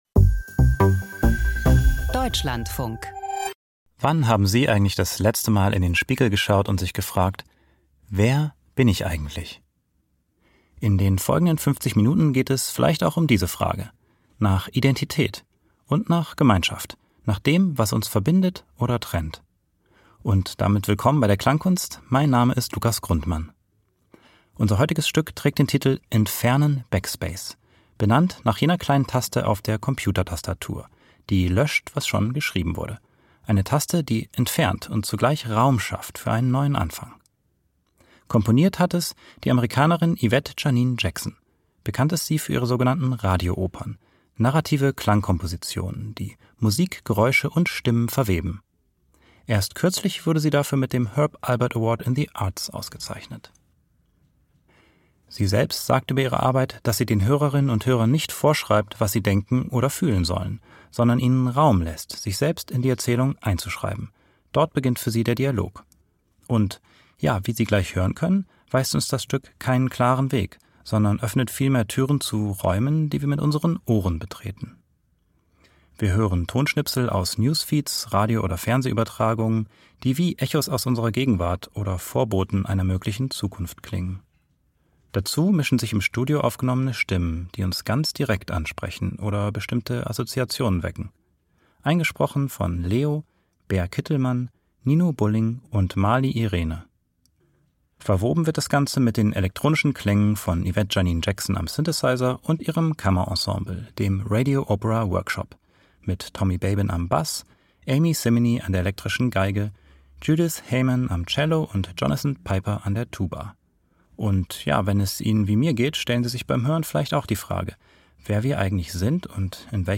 Klangkunst vor dem Hintergrund des Weltraumtourismus - Entf.